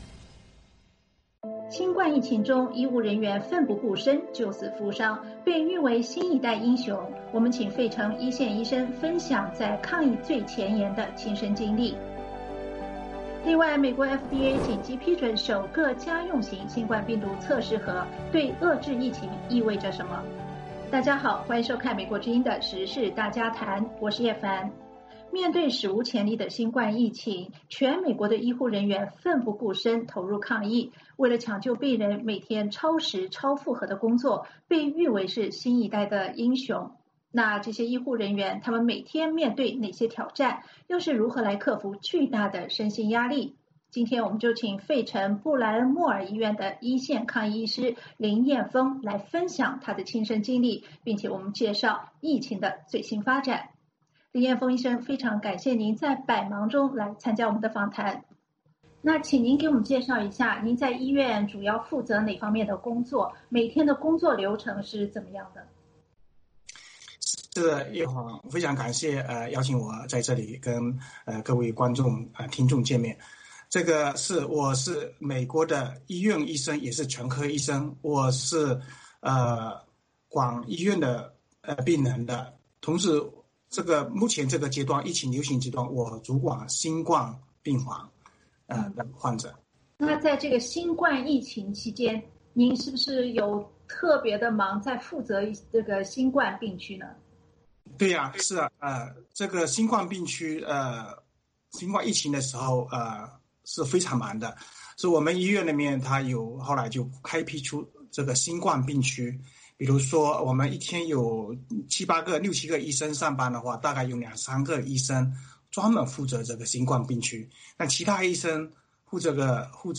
他对美国之音时事大家谈节目讲述了每天要面对哪些挑战，又是如何克服巨大的身心压力。